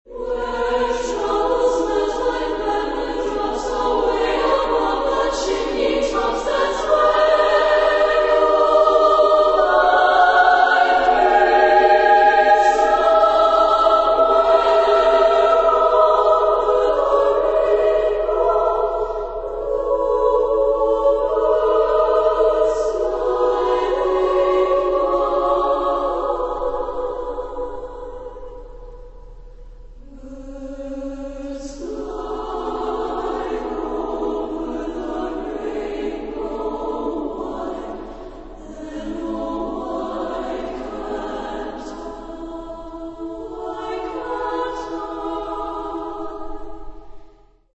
Género/Estilo/Forma: Canción
Carácter de la pieza : perplejo
Tipo de formación coral: SATB  (4 voces Coro mixto )
Instrumentación: Piano  (1 partes instrumentales)
Tonalidad : do mayor